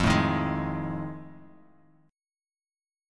F7#9 chord